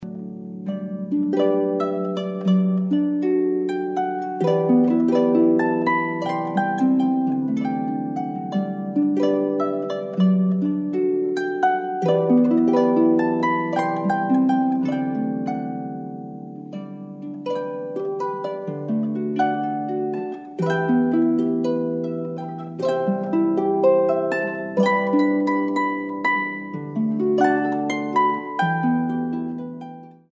This CD is a wonderful collection of harp music including